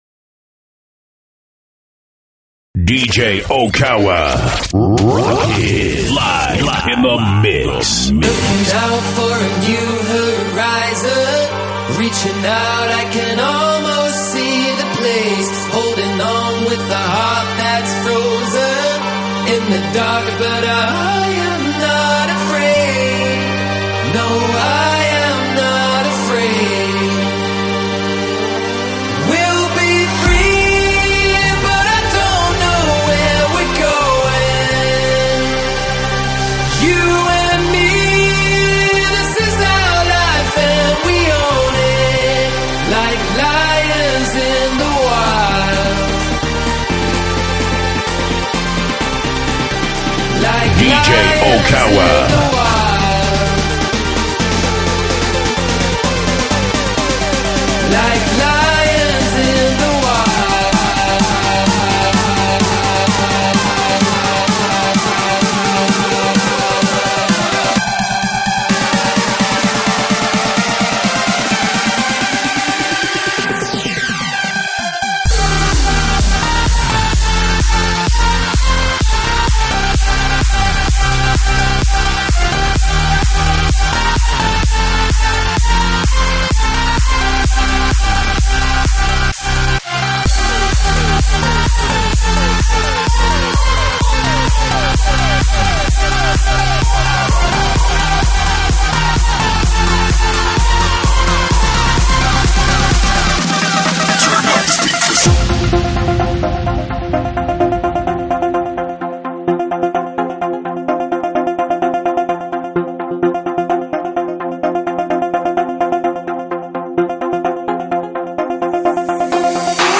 栏目： 慢摇舞曲